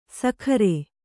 ♪ sakhare